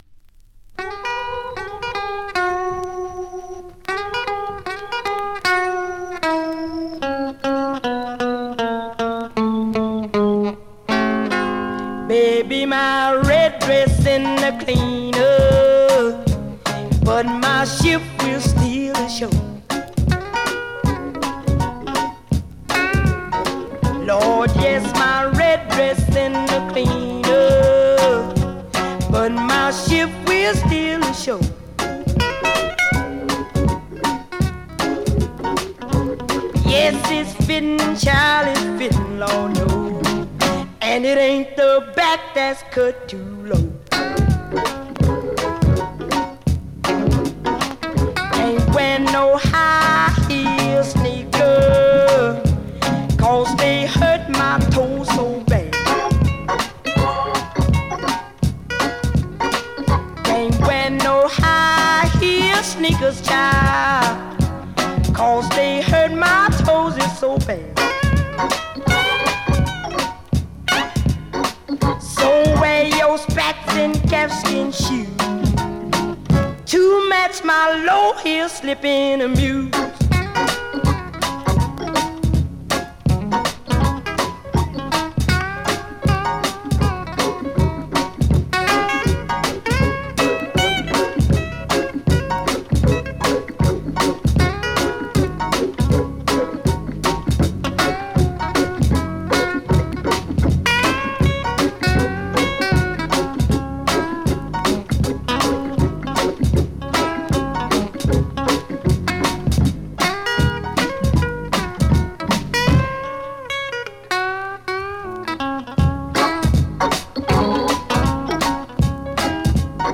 R&B, MOD, POPCORN , SOUL